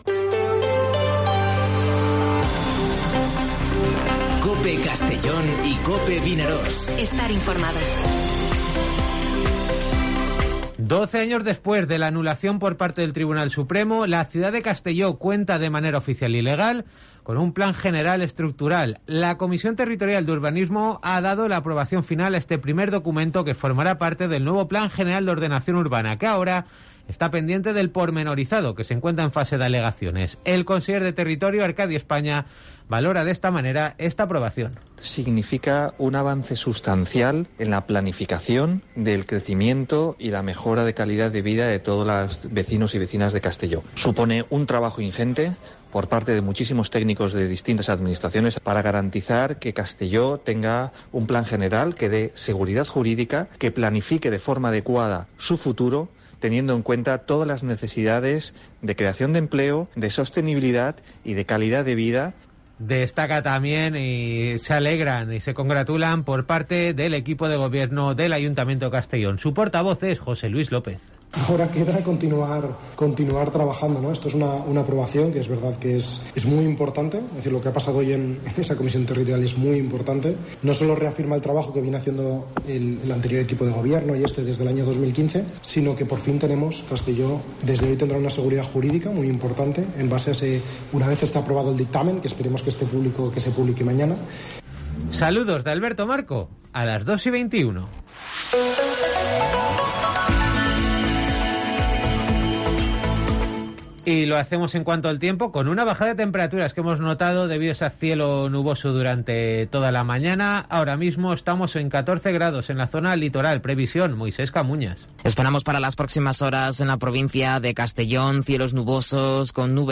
Informativo Mediodía COPE en Castellón (17/12/2021)